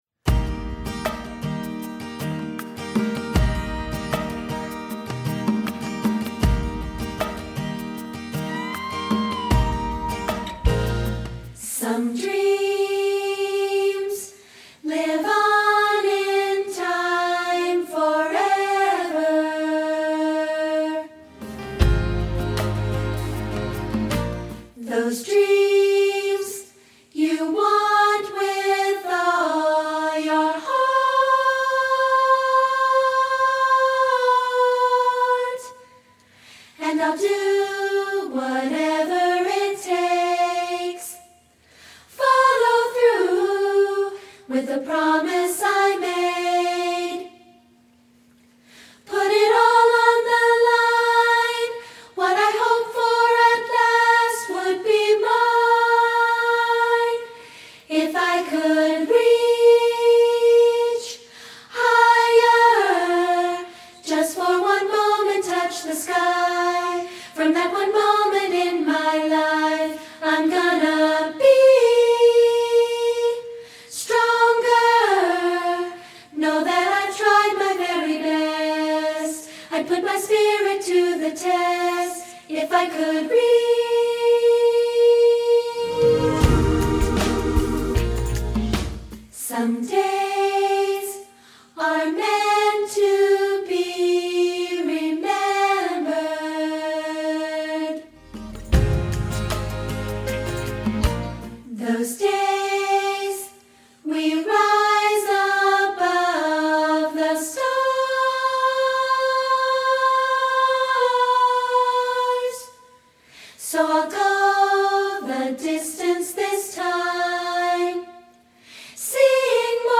Voc. Prac.